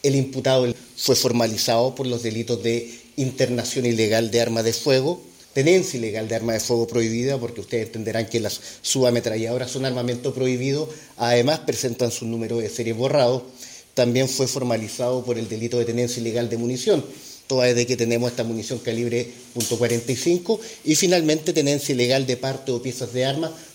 El fiscal de Lautaro, Miguel Velásquez, explicó que el hombre fue formalizado en el Juzgado de Garantía de la comuna por los delitos de internación ilegal de arma de fuego, tenencia ilegal de arma de fuego prohibida, tenencia ilegal de municiones y de partes o piezas de armas de fuego, quedando con la medida de prisión preventiva.